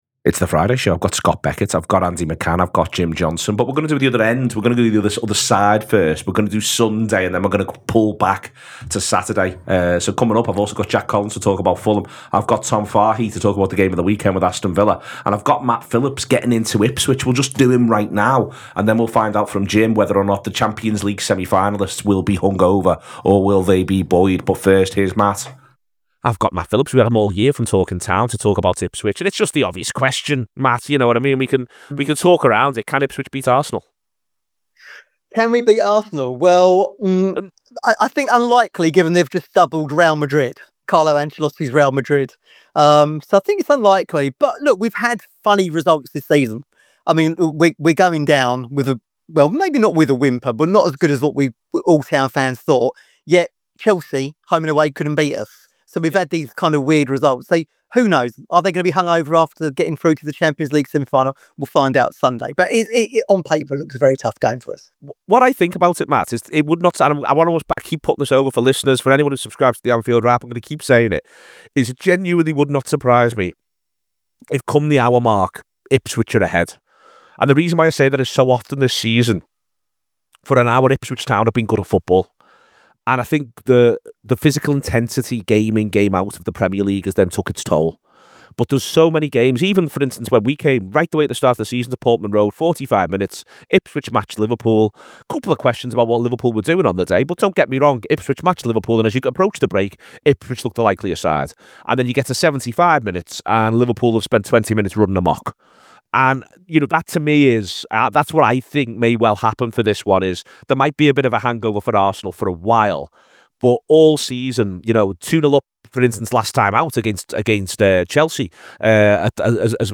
The Anfield Wrap’s preview show looking towards the weekends fixtures.